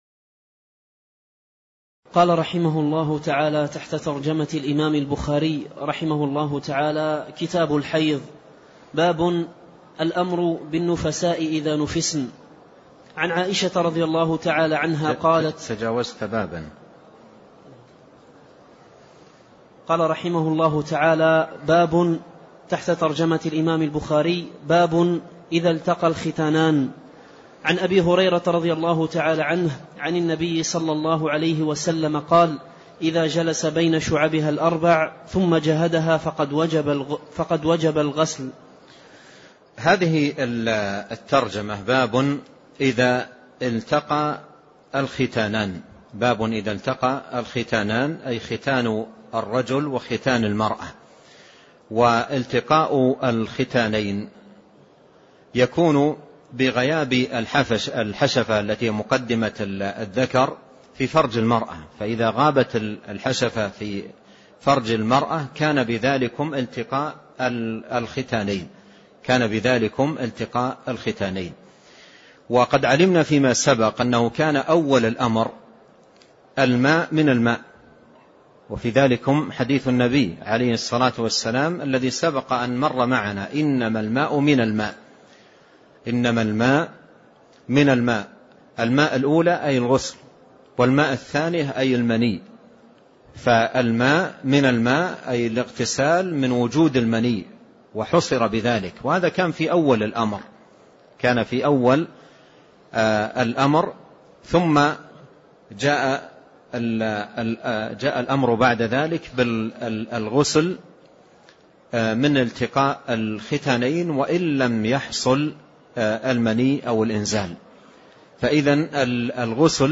تاريخ النشر ٢ جمادى الآخرة ١٤٣٣ هـ المكان: المسجد النبوي الشيخ